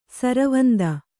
♪ saravanda